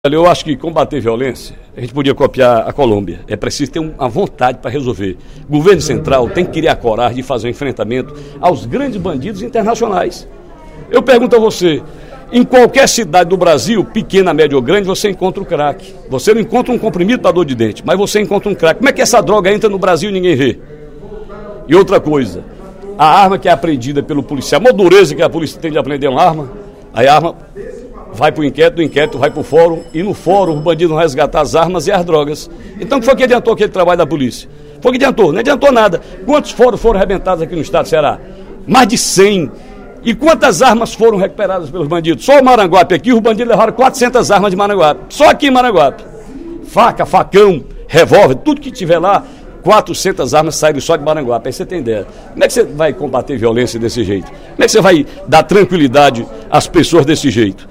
O deputado Ferreira Aragão (PDT) afirmou, em pronunciamento no primeiro expediente da sessão plenária da Assembleia Legislativa desta sexta-feira (19/02), que o grande problema da segurança pública é o narcotráfico.